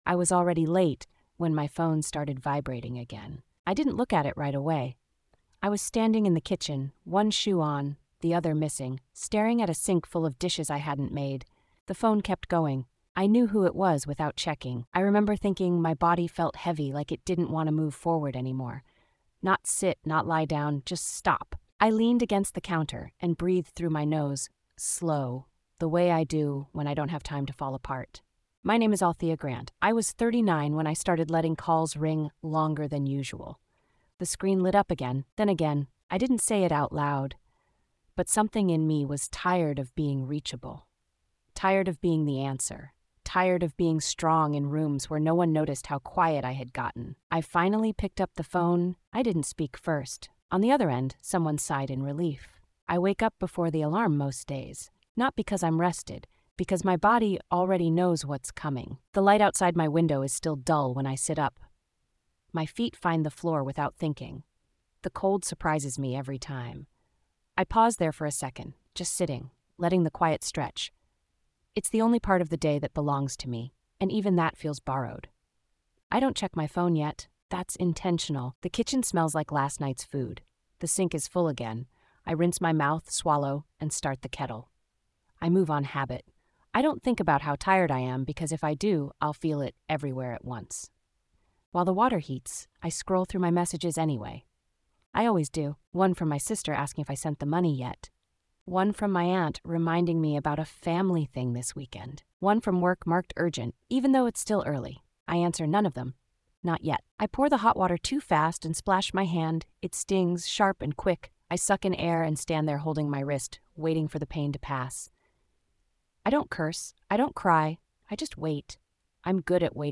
Women Tired of Being “Strong” is a first-person narrative told by a Black Caribbean woman living in Brooklyn who has spent her life being dependable, reliable, and emotionally unavailable to herself. The story follows her as she quietly reaches the edge of exhaustion from carrying family, financial, and emotional responsibility without permission to rest or fail.